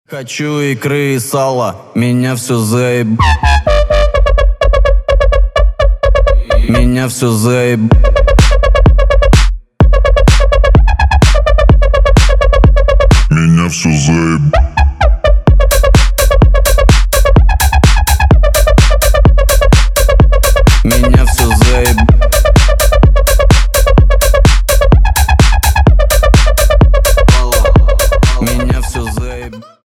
жесткие
мощные басы
Bass House
качающие
смешные
рейв